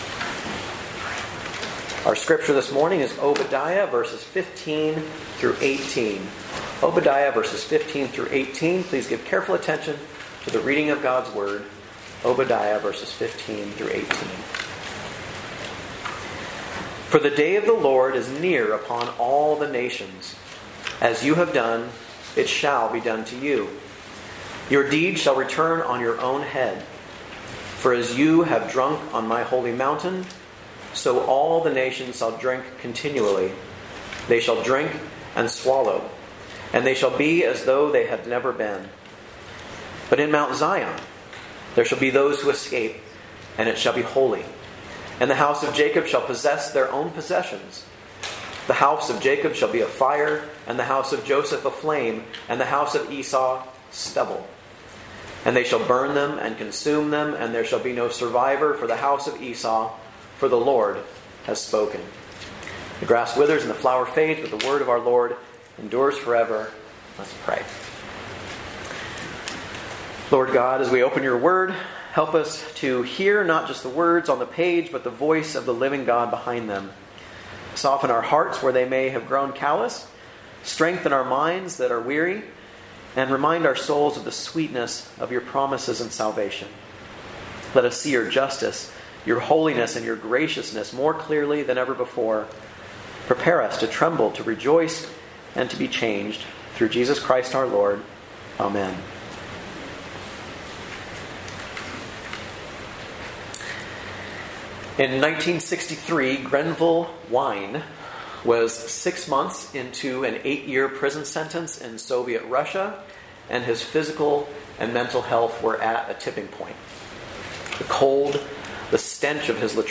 From Series: "Guest Preacher"